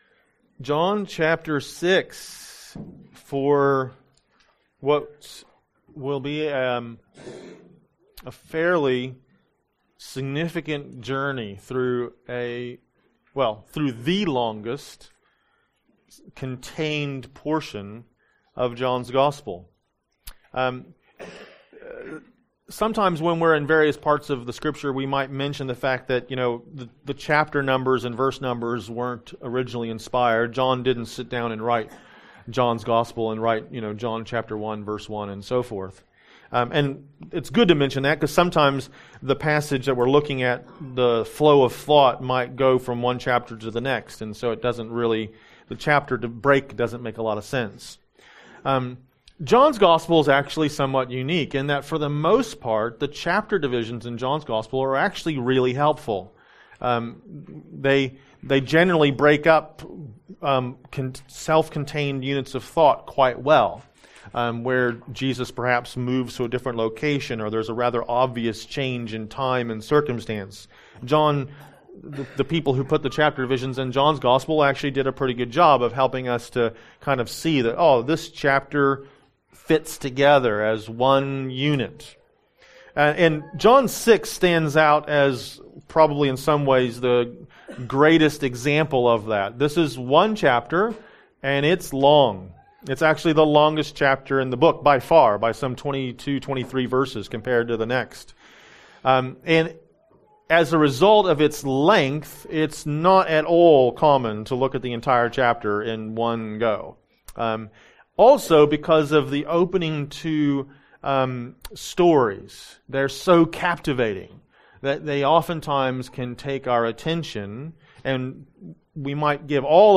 Sermons & Seminars | Rolleston Baptist Church